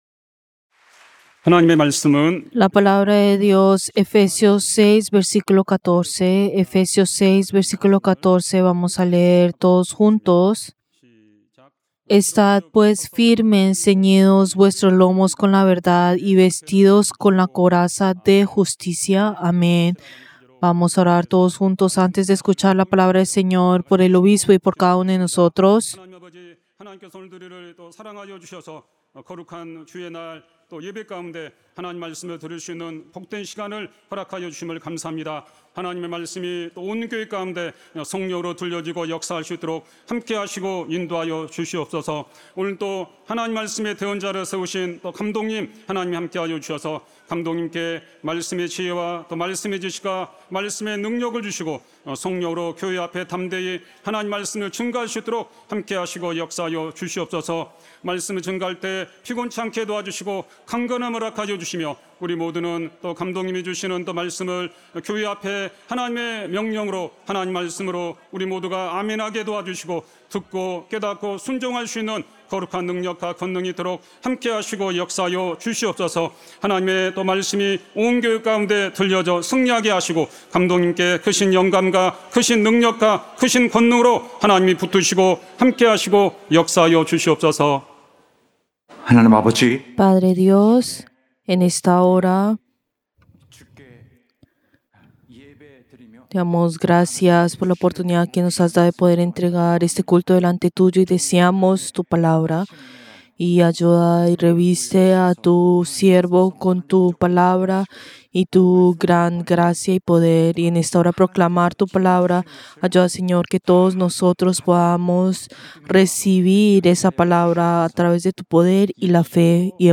Servicio del Día del Señor del 6 de julio del 2025